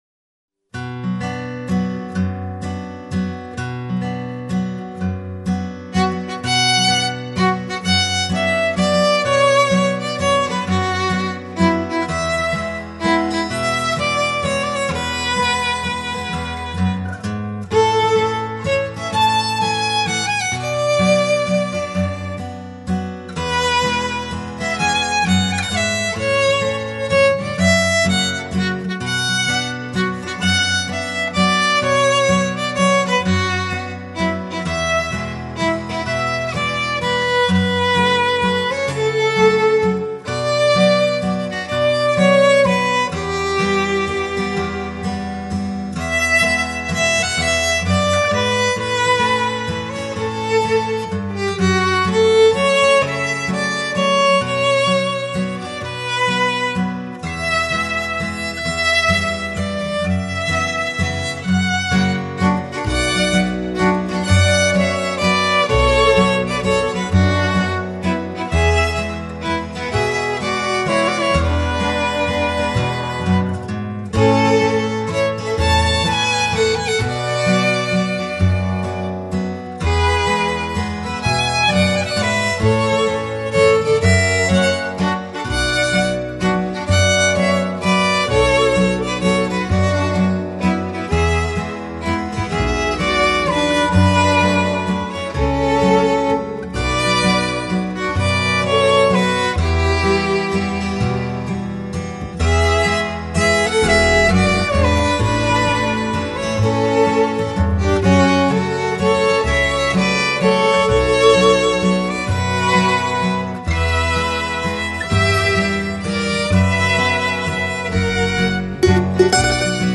流派：folk
曲儿婉转、悠扬，仿佛天堂中飘来的雀鸟们在歌唱。